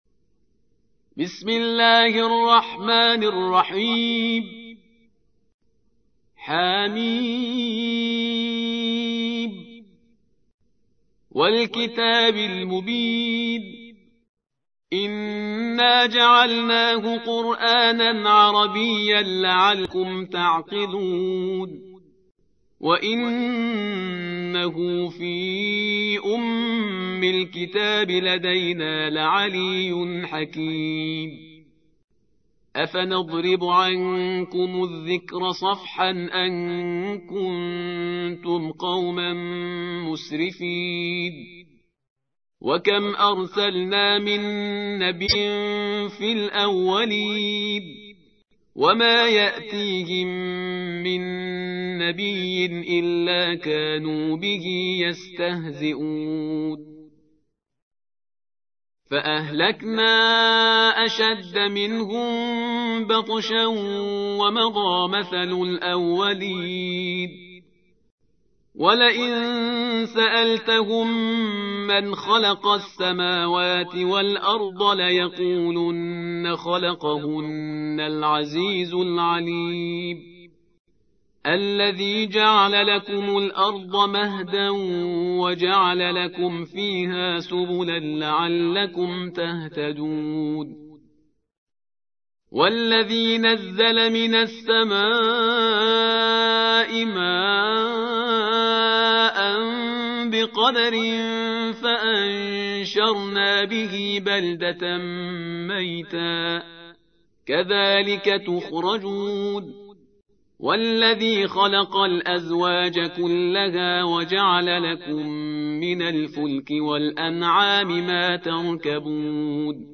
43. سورة الزخرف / القارئ
القرآن الكريم